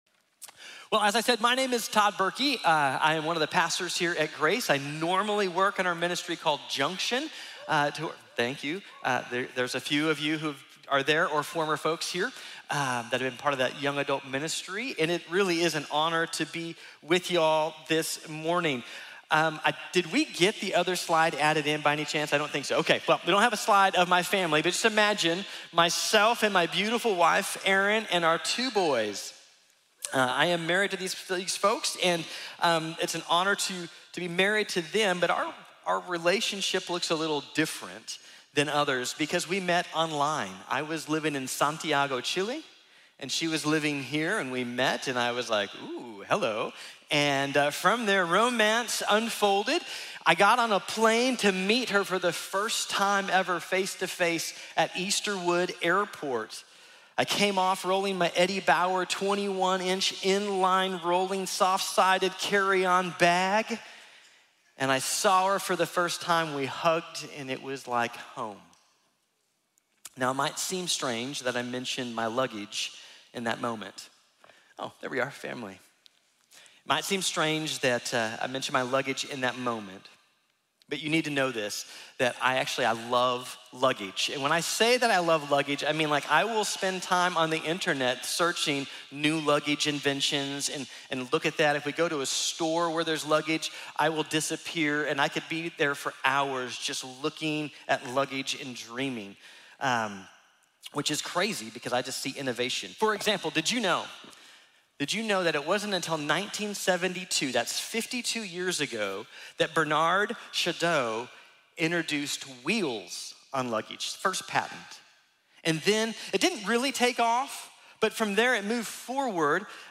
La invitación de las promesas de Dios | Sermón | Iglesia Bíblica de la Gracia